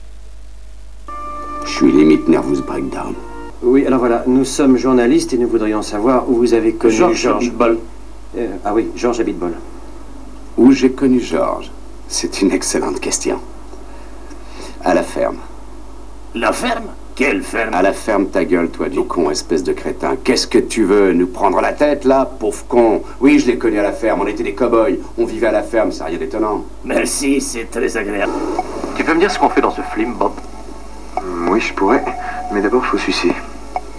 je me suis fait des petites compiles avec des extraits du films si vous voulez.....